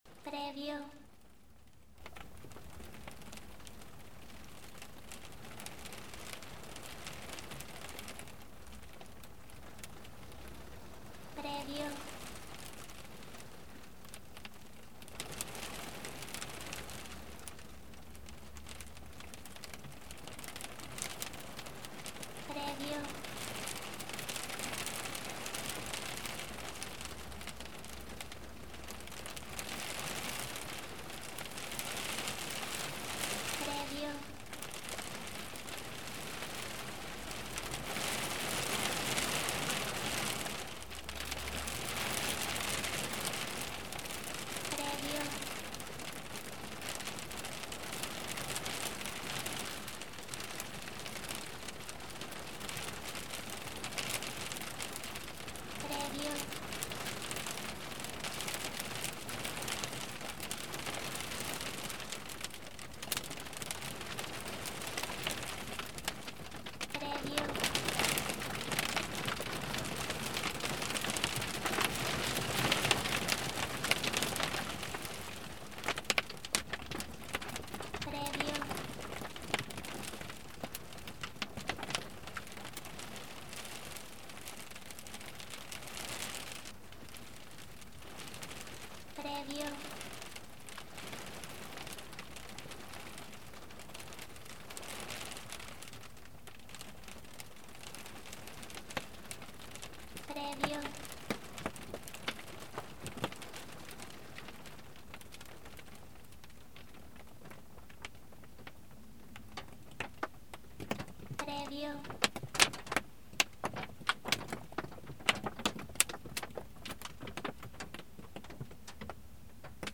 Hail Storm in the Car
Hail and rain, one of the autumn storms, recorded from the car. You can hear the hailstones bombarding the window.
HailStormintheCar-preview.mp3